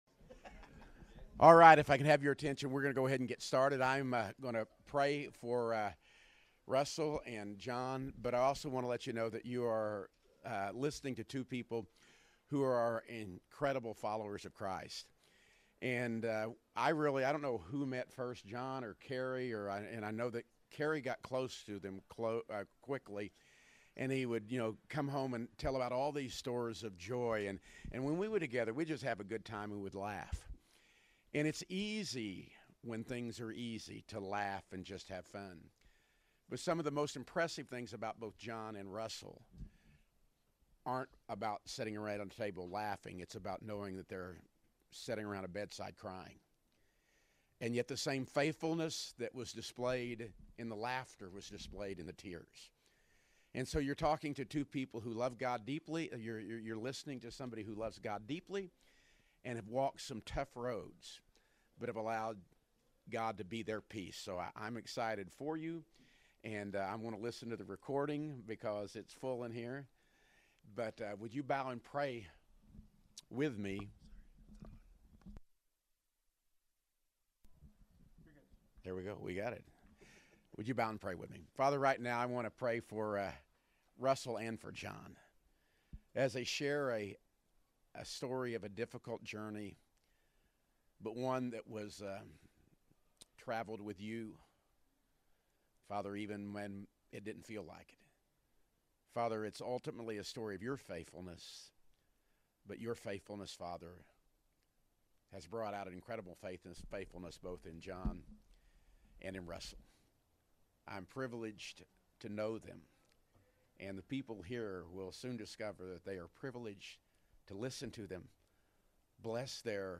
Series: "I AM" Refresh Retreat 2026